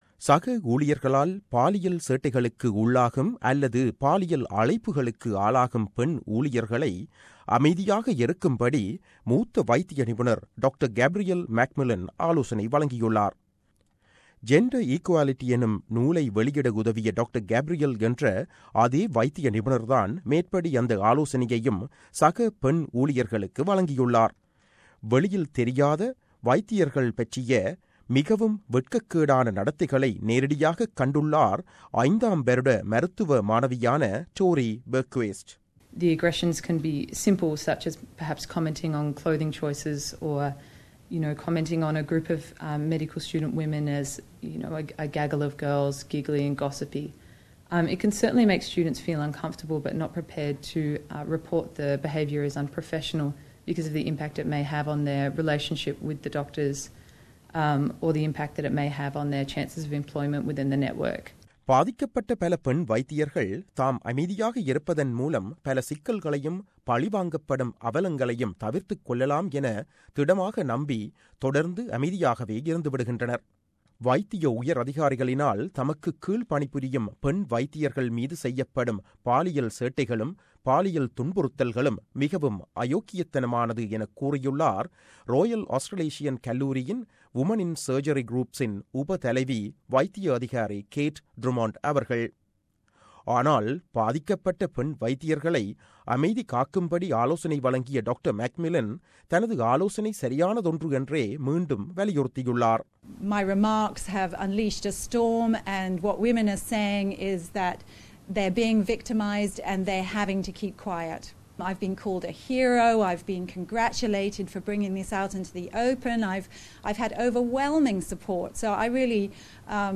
செய்தி விவரணம்.